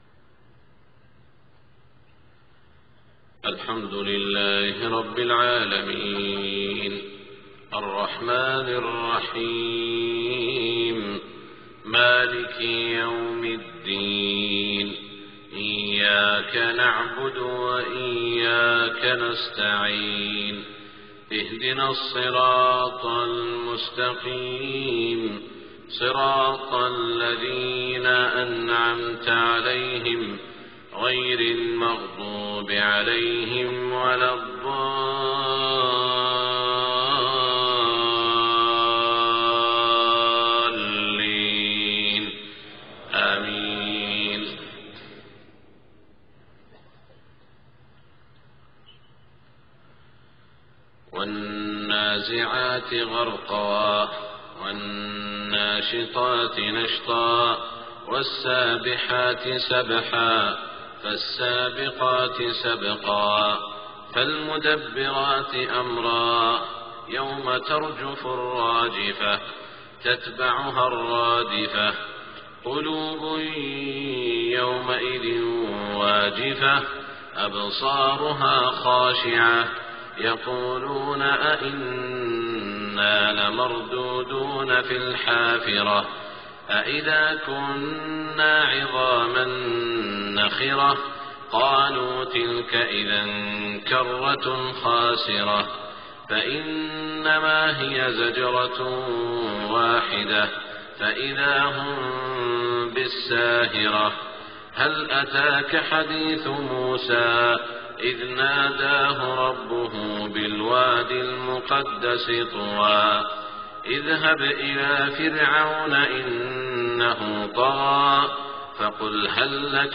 صلاة الفجر 4-5-1427 من سورتي النازعات و الانفطار > 1427 🕋 > الفروض - تلاوات الحرمين